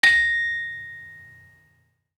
Gamelan Sound Bank
Saron-4-B5-f.wav